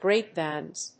/ˈgreˌpvaɪnz(米国英語), ˈgreɪˌpvaɪnz(英国英語)/